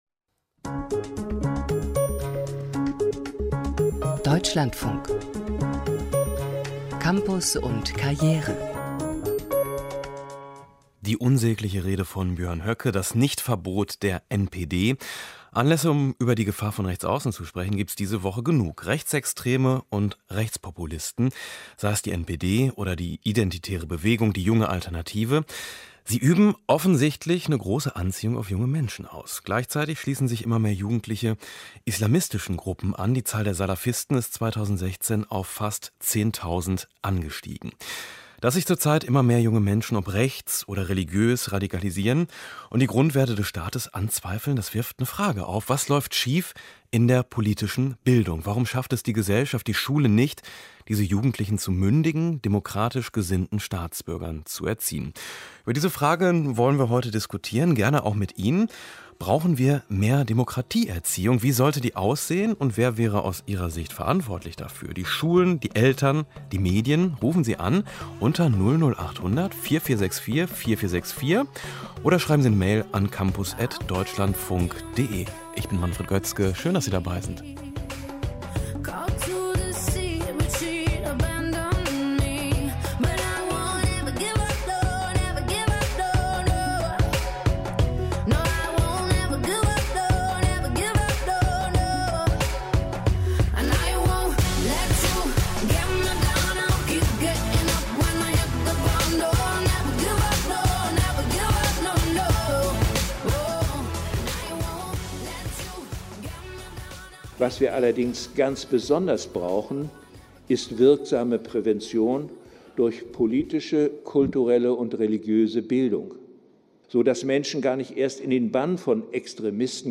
Wie muss eine gelungene politische Bildung aussehen, um junge Menschen zu Demokraten zu machen? Gesprächsgäste: